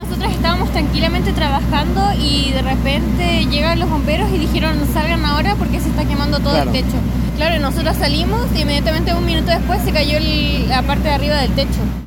Una trabajadora del lugar indicó que la evacuación ordenada por Bomberos evitó una tragedia.